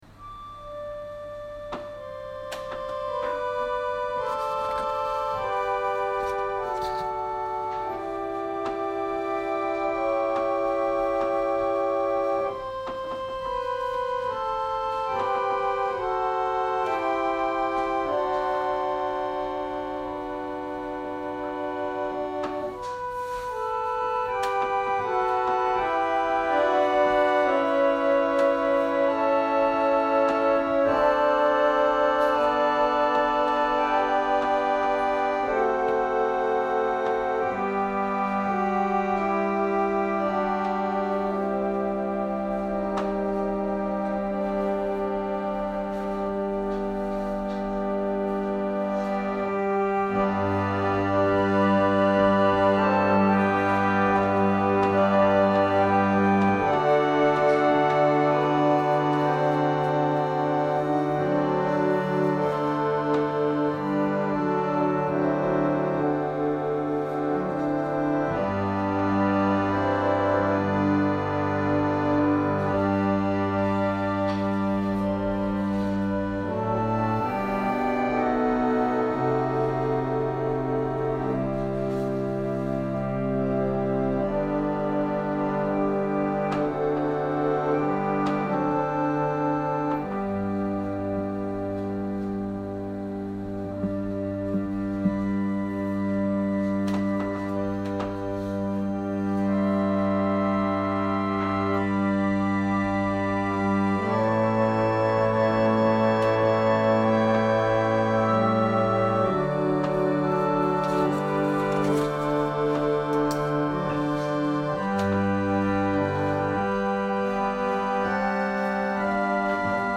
説教アーカイブ。
私たちは毎週日曜日10時20分から12時まで神様に祈りと感謝をささげる礼拝を開いています。